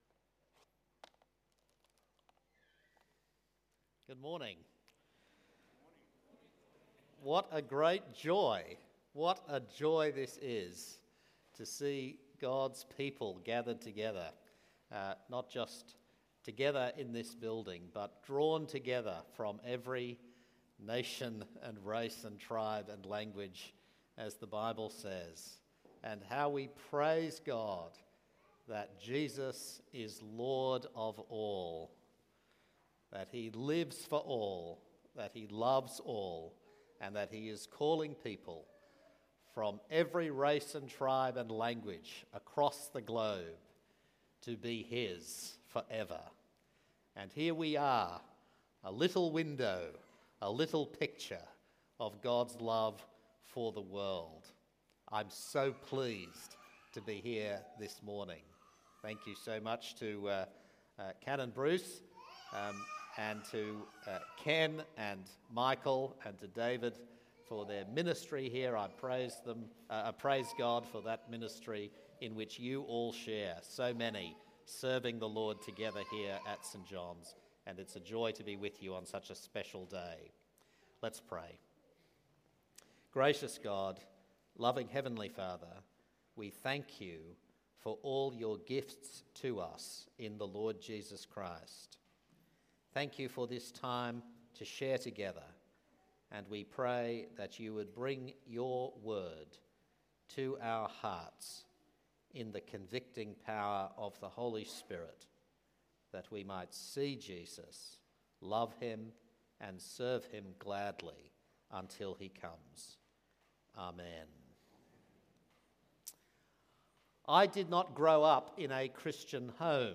Sunday sermon by Archbishop Kanishka Raffel on Ephesians 3:14-21 from St John’s Anglican Cathedral Parramatta.